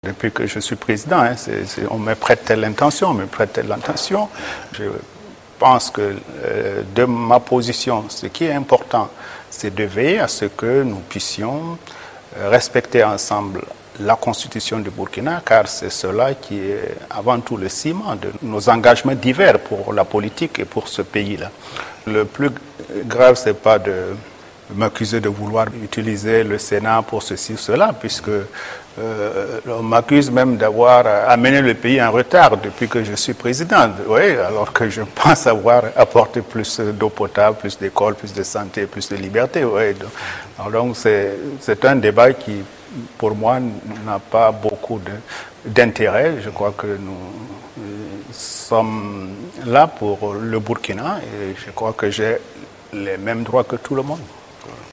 Si beaucoup de ses opposants dont Zéphirin Diabré l’accusent de vouloir modifier la constitution, le président Blaise Compaoré est d’avis contraire. Ecouter Blaise Compaoré_ dans des déclarations à la Radiodiffusion Télévision du Burkina, le lundi 5 août.